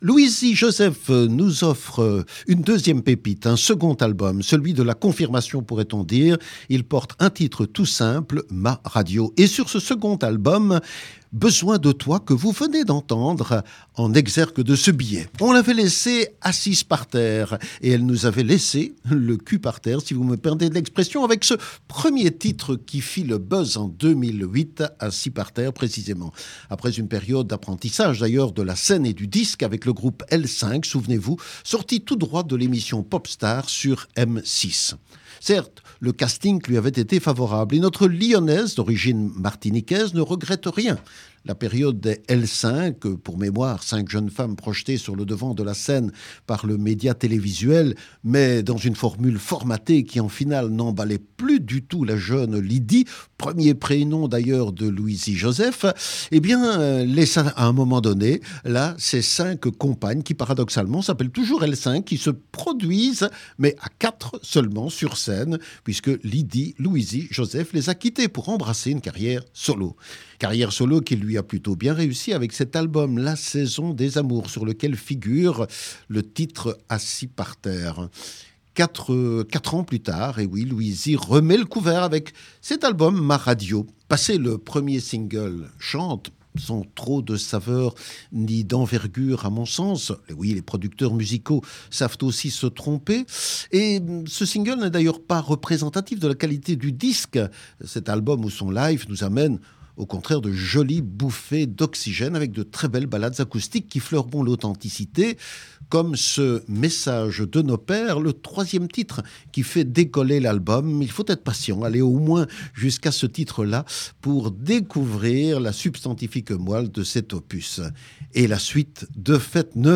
Un fil conducteur entre pop, soul et reggae.